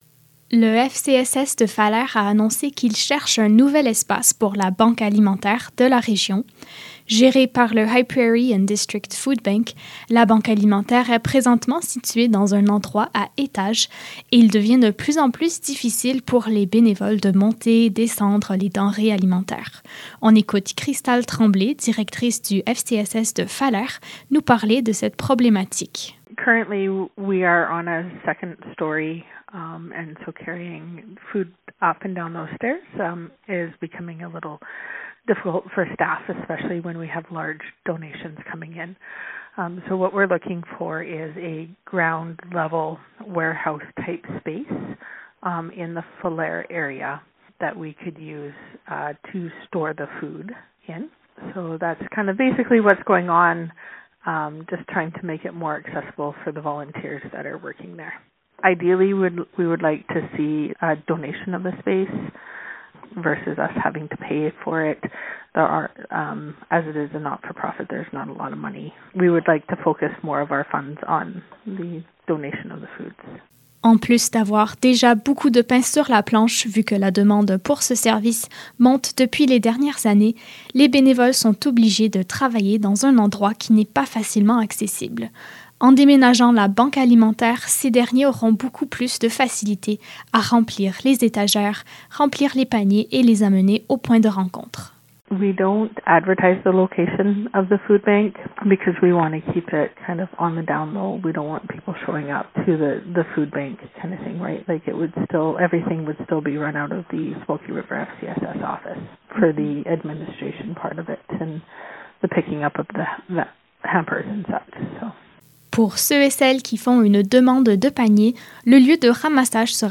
Reportage-Banque-alimentaire.mp3